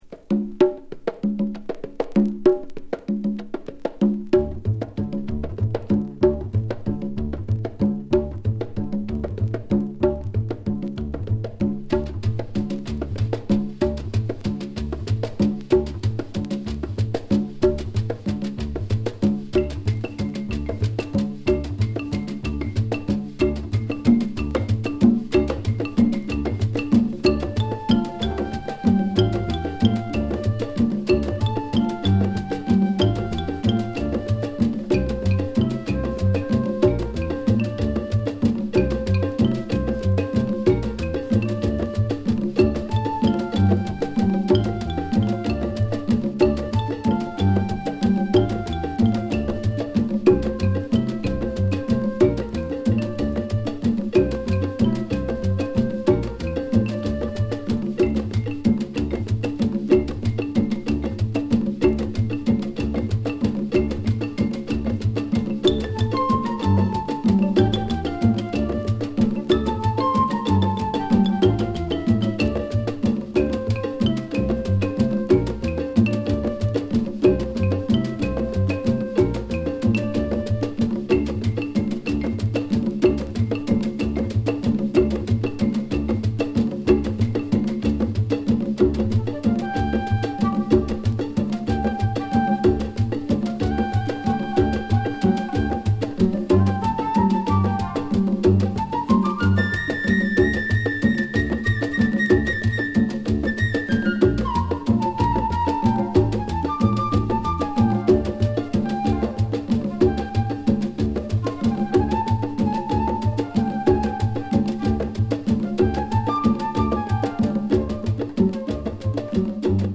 全編通してアフロ・キューバンなパーカッショングルーヴが炸裂です。